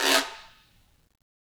Percussion
Guiro-Fast_v1_Sum.wav